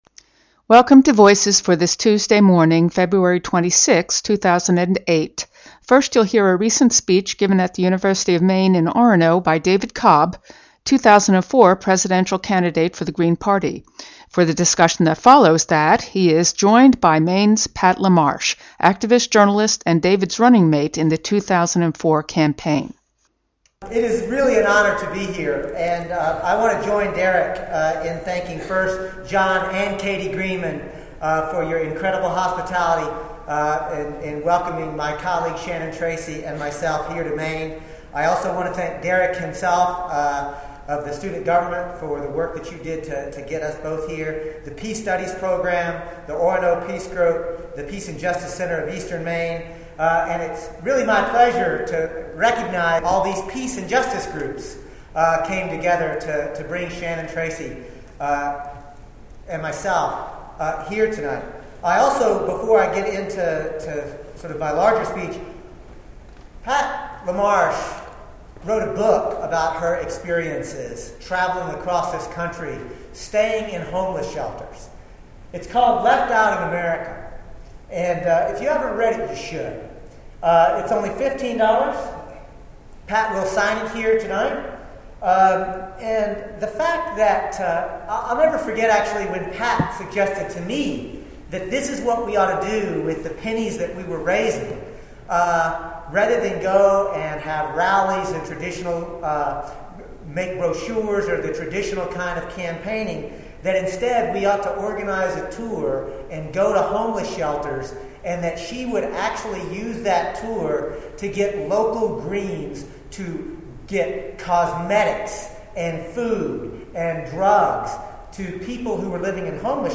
speaking at the University of Maine.